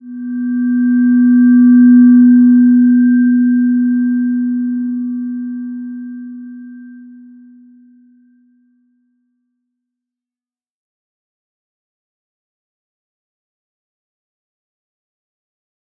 Slow-Distant-Chime-B3-mf.wav